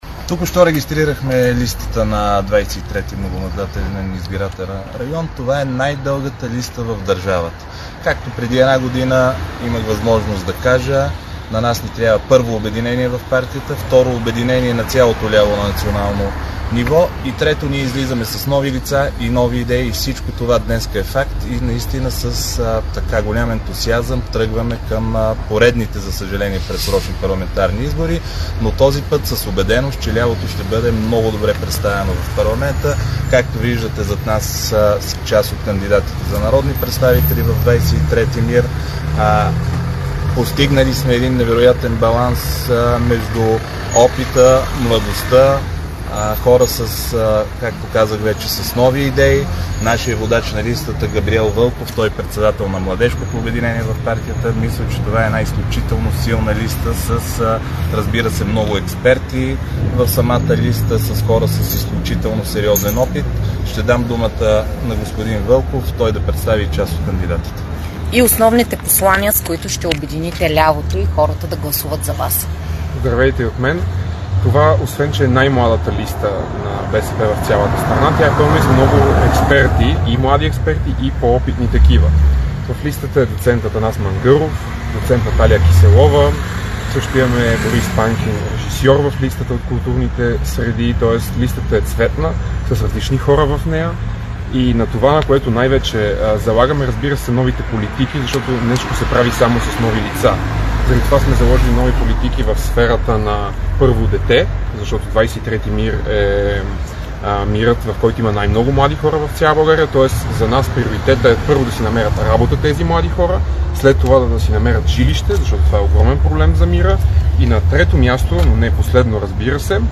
Директно от мястото на събитието
Брифинг
- директно от мястото на събитието (ул.